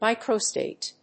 mícro・stàte